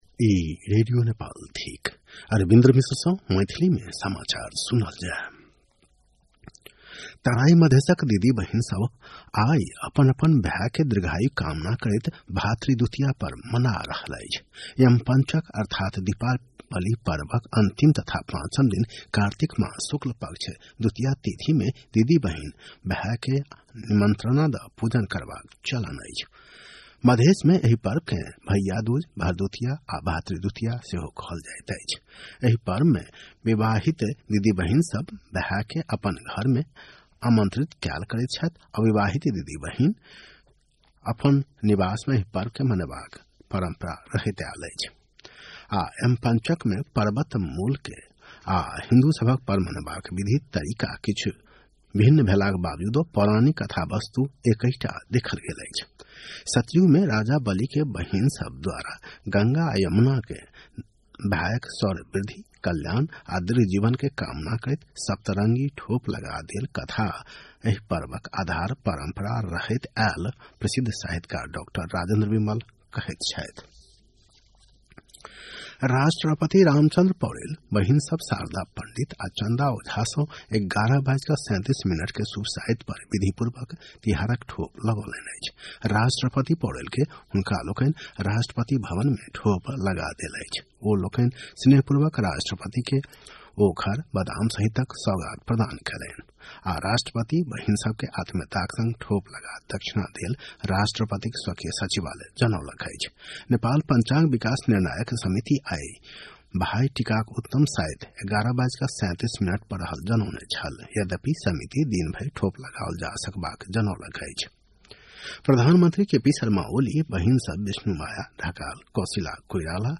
मैथिली भाषामा समाचार : १९ कार्तिक , २०८१